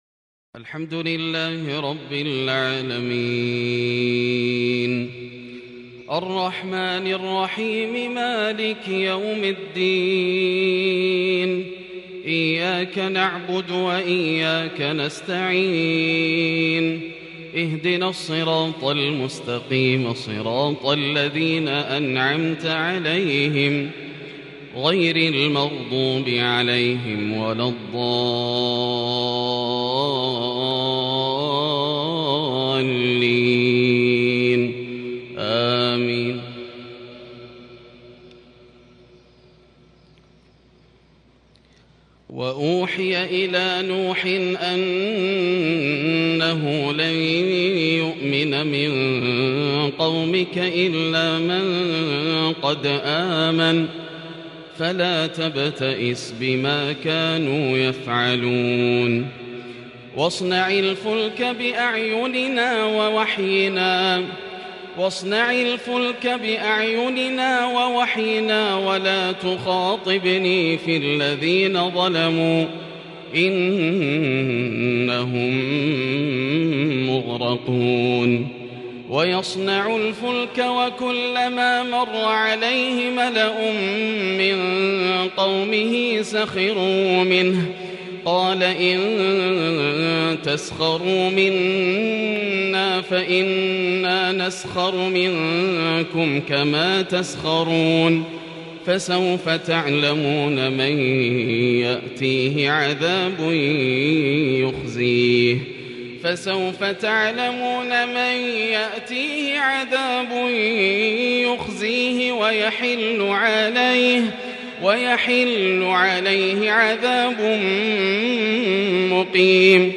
صلاة فجر ٢٣ صفر١٤٤٢هـ | من سورتي هود و العنكبوت | Fajr prayer from Surat -Hud and Surat AlAnkabut | 10/10/2020 > 1442 🕋 > الفروض - تلاوات الحرمين